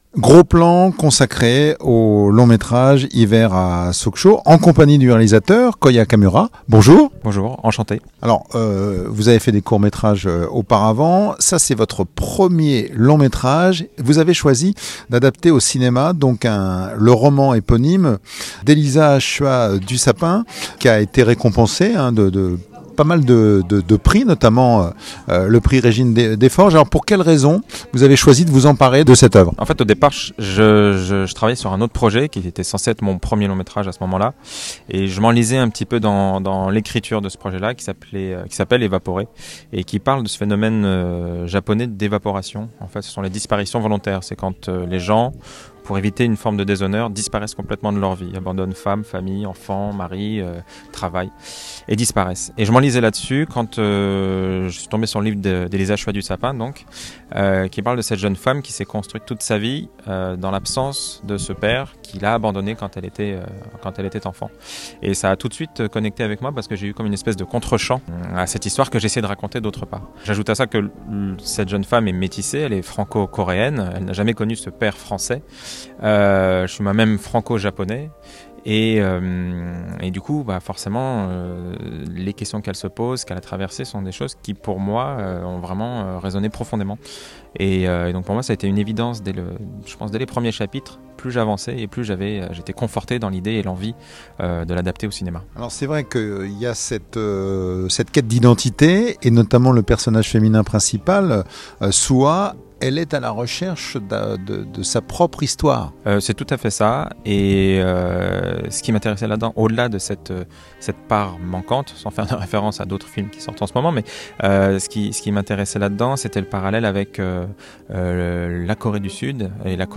Lors de cette interview